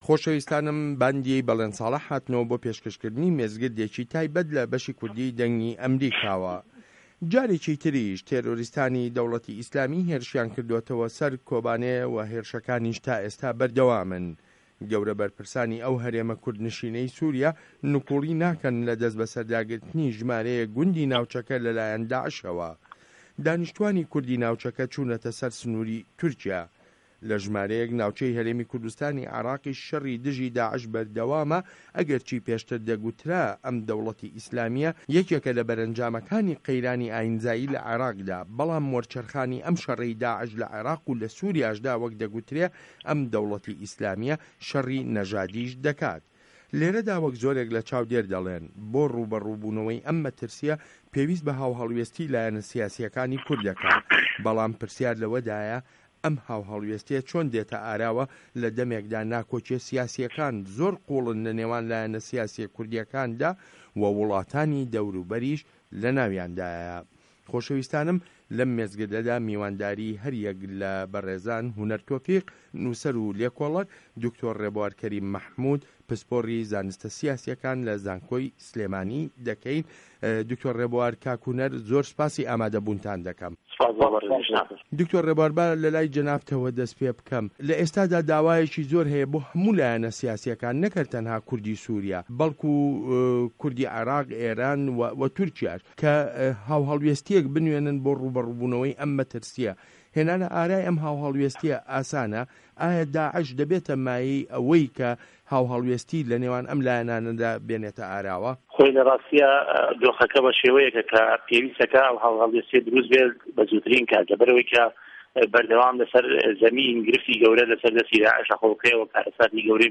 مێزگرد: داعش و هاوهه‌ڵوێستی کورد